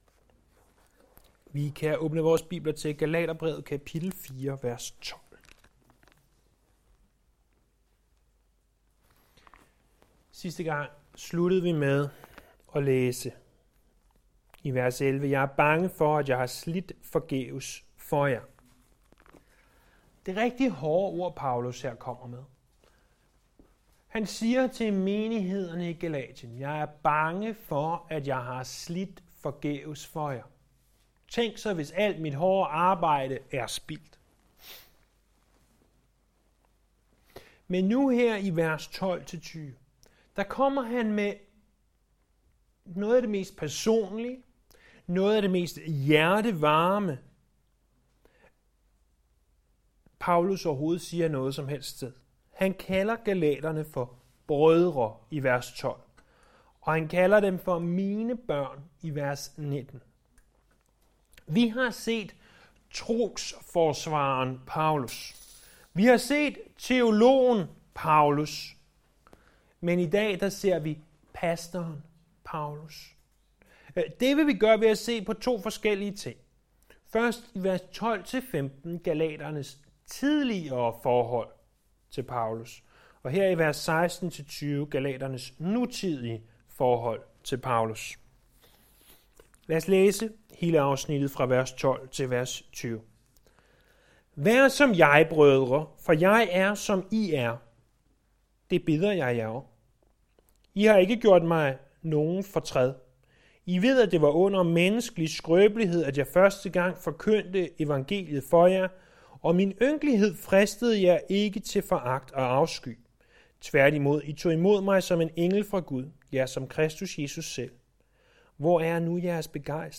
Calvary Chapel | Bibelundervisning | Galaterbrevet
Bibelundervisning fra Nye Testamente fra Galaterbrevet (Gal).